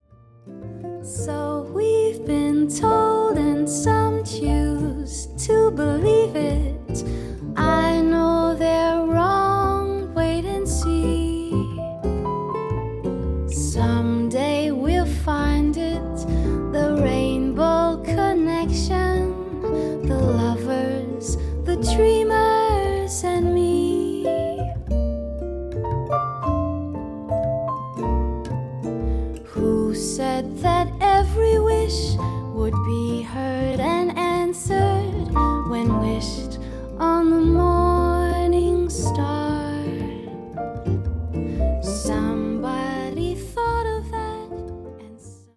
vocal
bass, piano, vibraphones
gutiar
cello